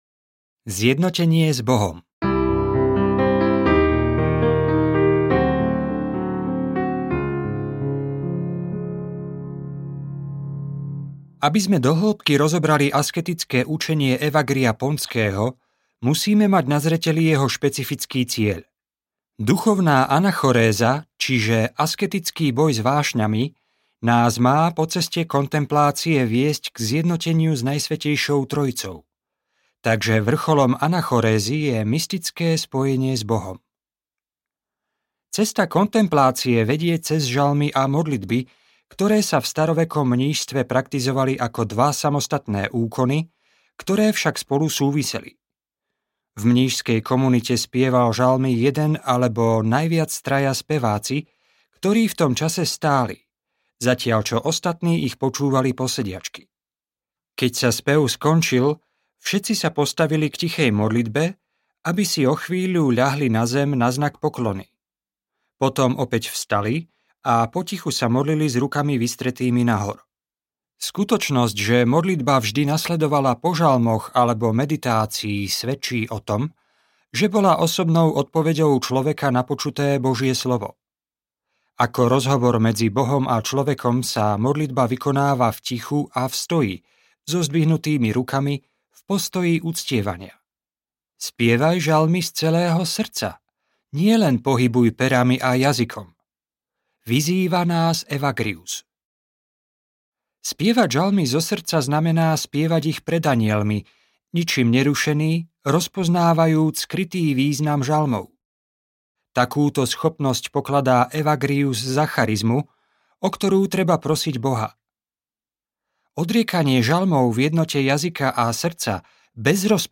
Duchovný boj púštnych otcov audiokniha
Ukázka z knihy